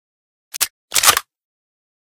unjam_empty.ogg